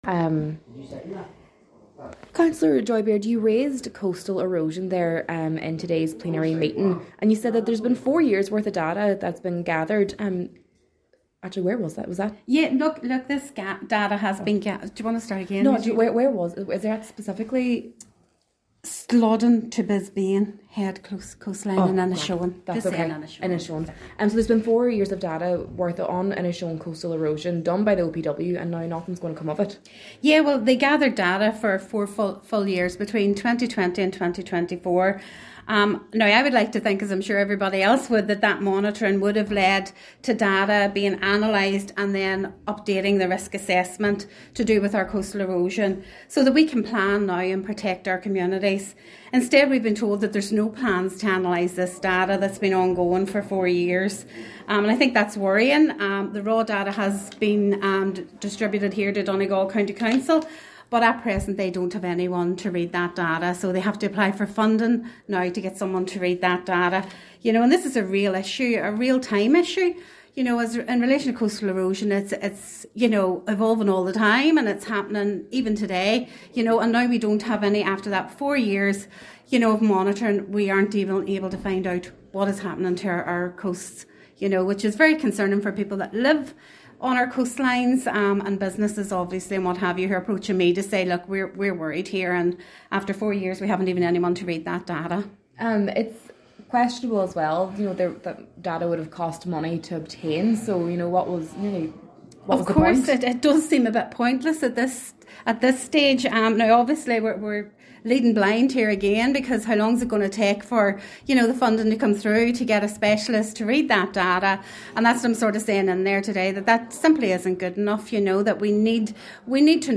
Cllr Beard said that despite the research being completed, it will not be used to implement protective measures along the Donegal coastline: